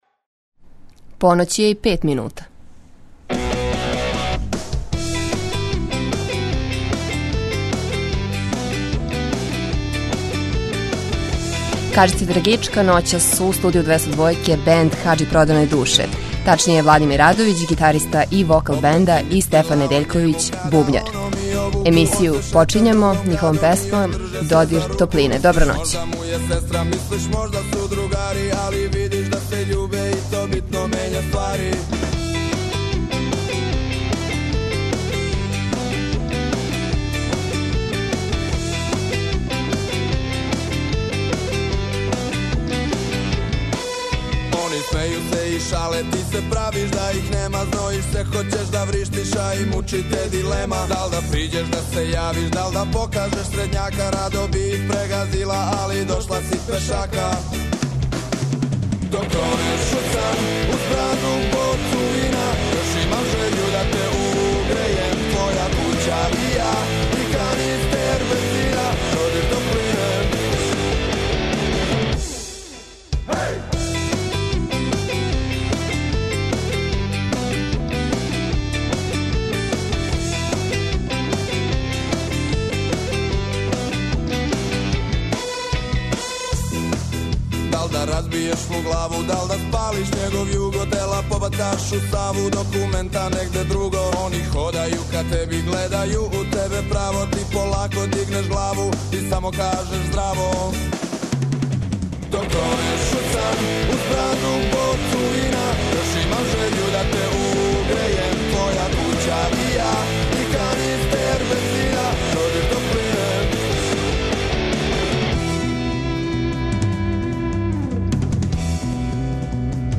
уживо у студију